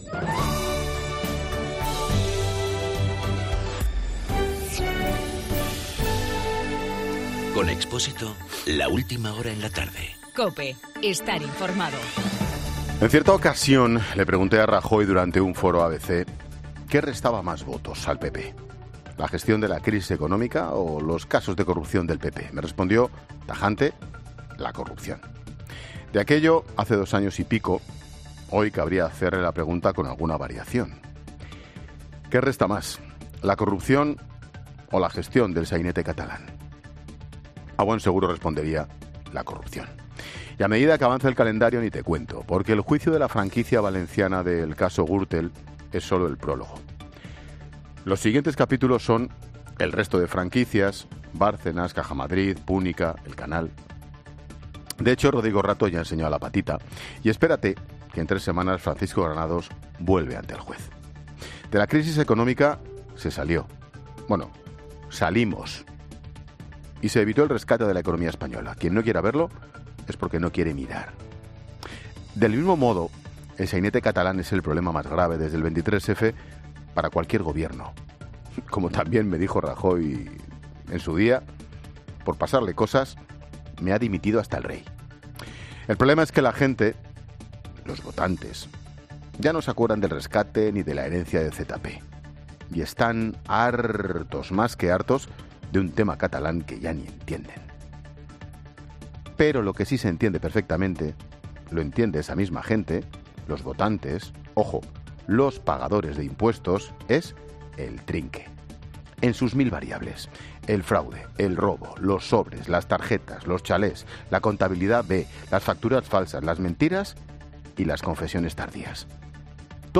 Monólogo de Expósito
Ángel Expósito comenta en su monólogo de las 18 horas los diferentes problemas con los que ha tenido